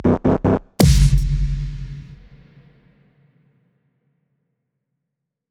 FX – 128 – IMPACT
FX-128-IMPACT.wav